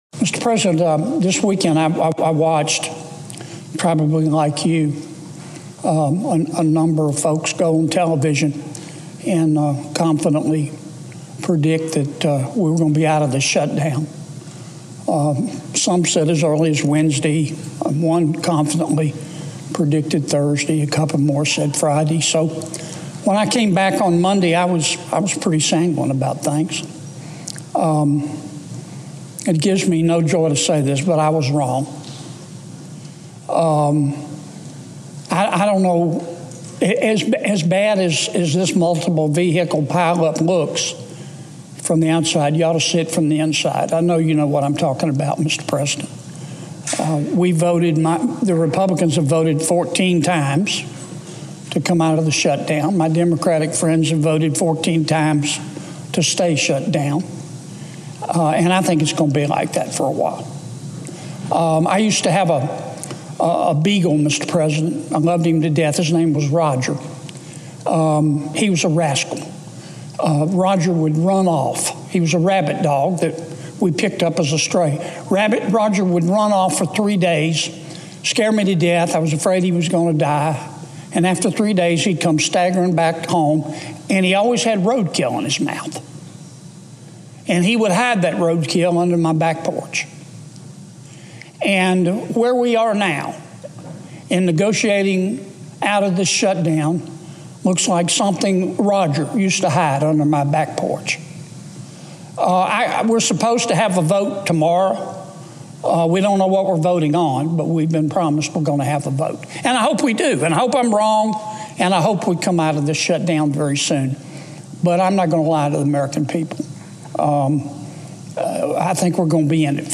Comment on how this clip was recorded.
delivered 5 November 2025, U.S. Capitol Building, Washington, D.C. Audio Note: AR-XE = American Rhetoric Extreme Enhancement